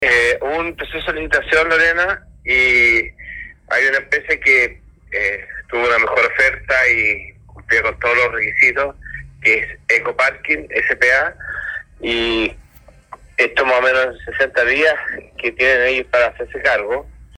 El jefe comunal aseguró en una entrevista radial que la empresa Ecoparking S.P.A. asumirá la administración de los parquímetros, pero un concejal aclara que el proceso aún no está resuelto
CURICÓ, REGIÓN DEL MAULE – La mañana de este miércoles, en una entrevista con Radio Condell, el alcalde de Curicó, George Bordachar  (IND-exRN), afirmó que la empresa Ecoparking S.P.A. sería la nueva concesionaria de los parquímetros en la ciudad.
extracto-entrevista-alcalde-bordachart-en-radio-condell.mp3